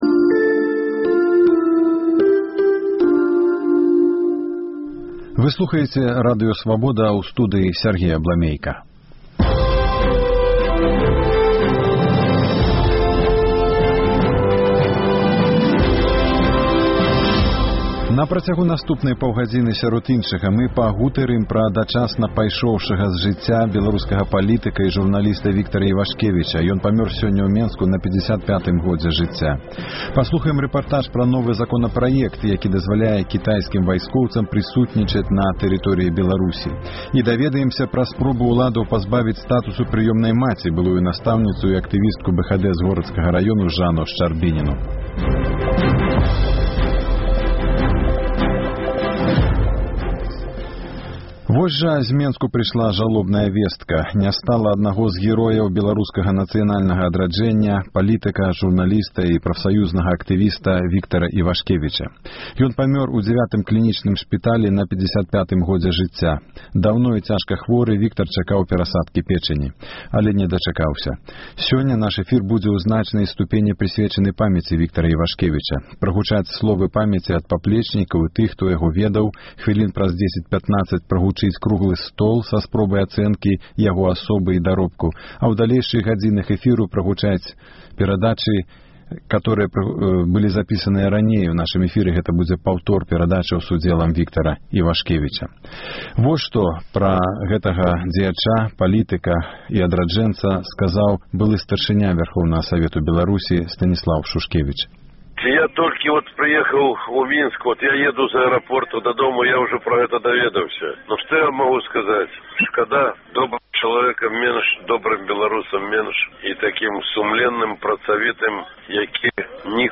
У праграме – круглы стол